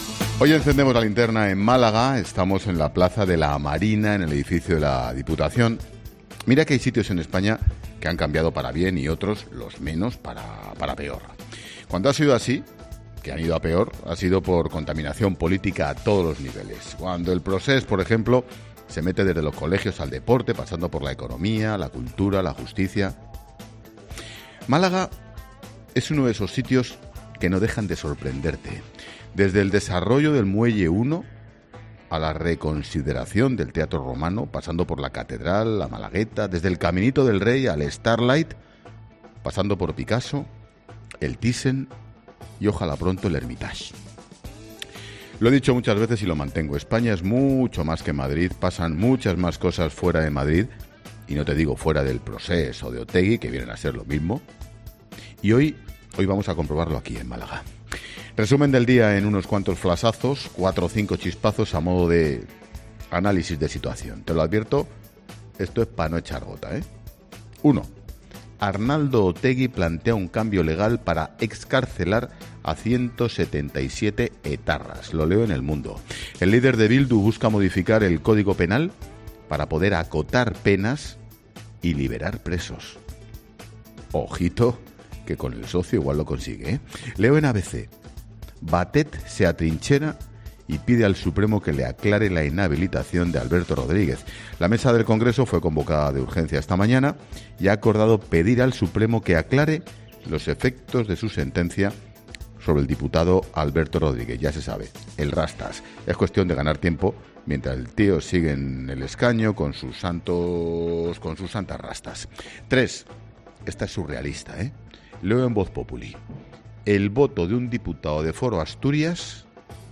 Monólogo de Expósito
El director de 'La Linterna', Ángel Expósito, resume las informaciones del día desde Málaga